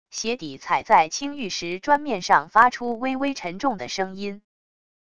鞋底踩在青玉石砖面上发出微微沉重的声音wav音频